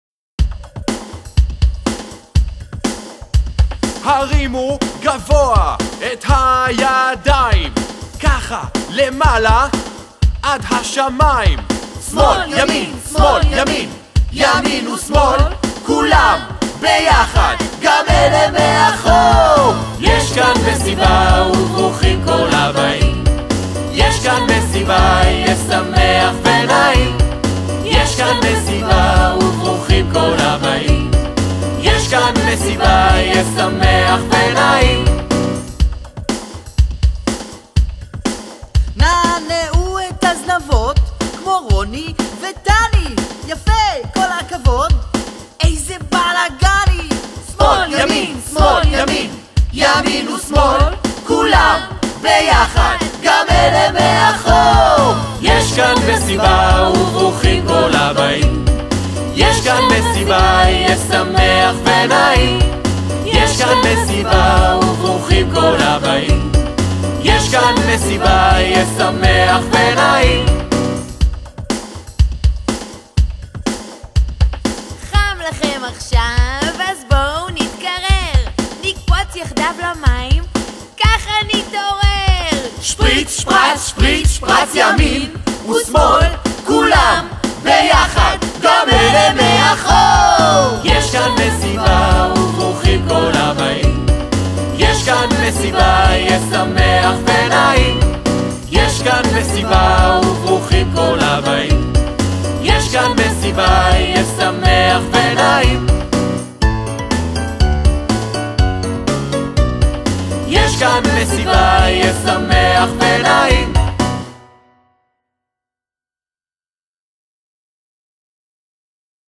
מבחר שירים מתוך ההצגה :